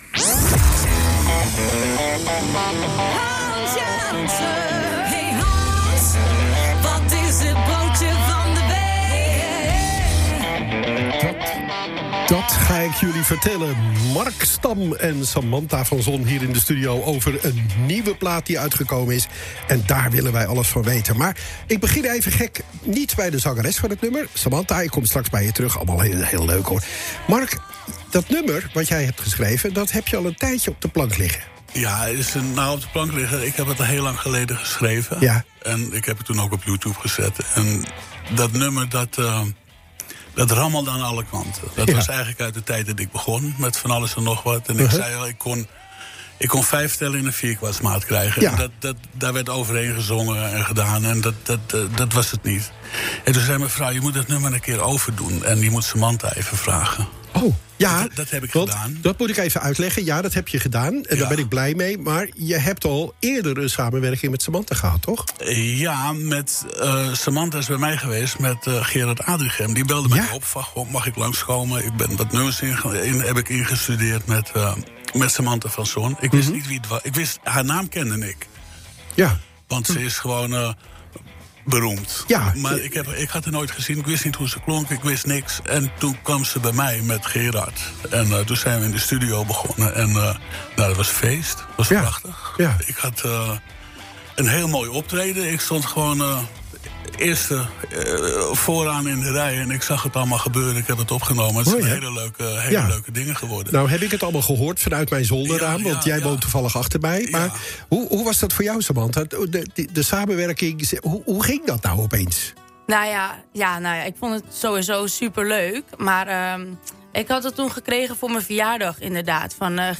(Het gesprekje is in twee delen geknipt, de track is er tussenuit te beluisteren….)